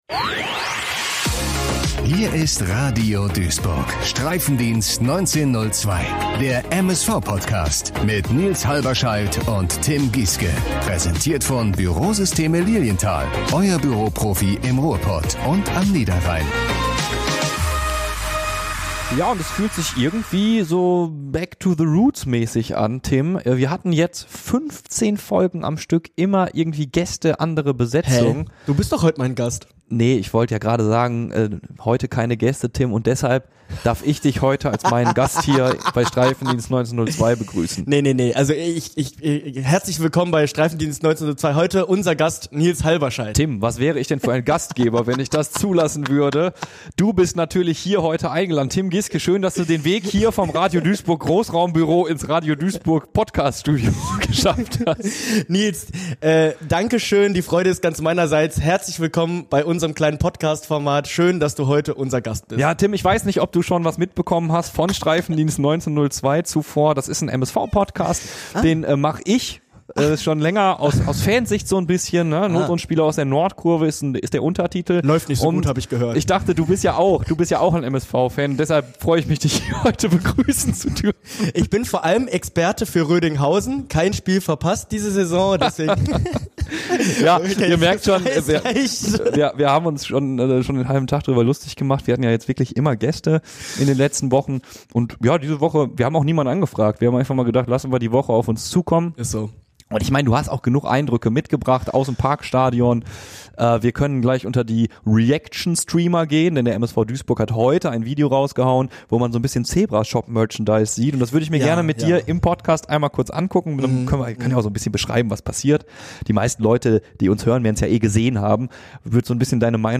Denn: sie haben keinen Gast.